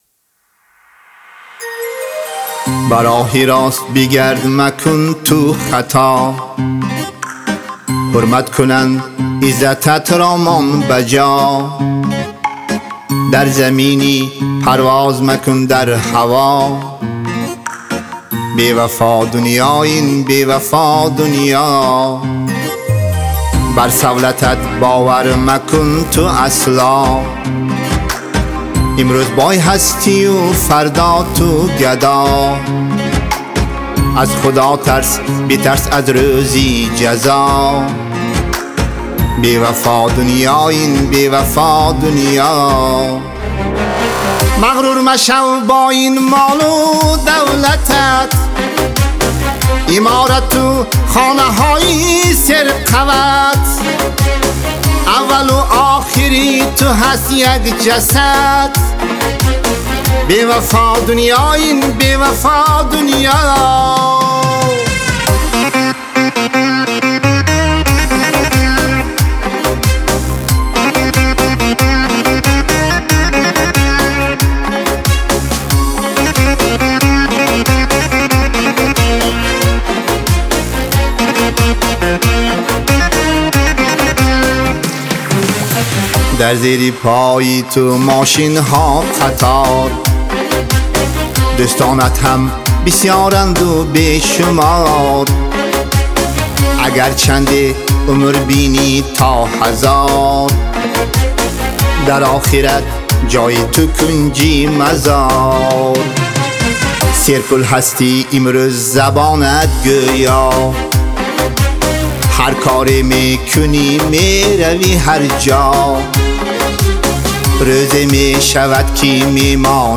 бо садои хонандаи тоҷик